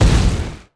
explo.wav